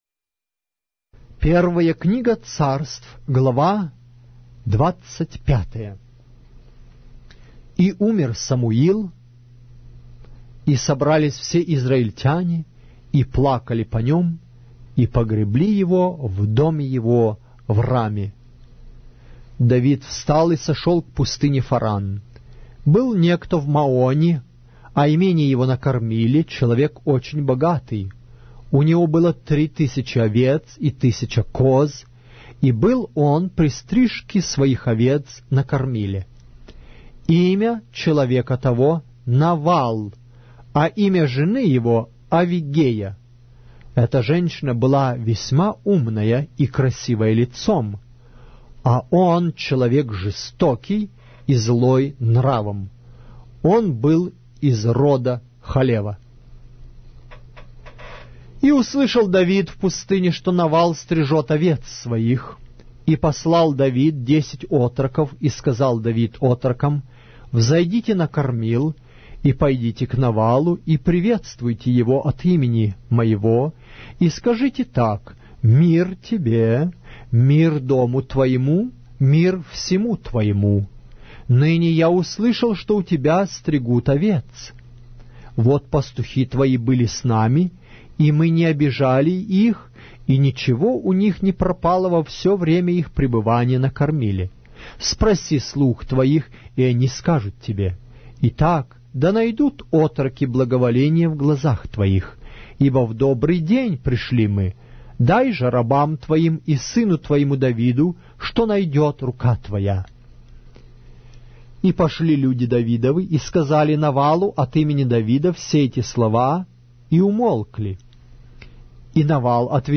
Глава русской Библии с аудио повествования - 1 Samuel, chapter 25 of the Holy Bible in Russian language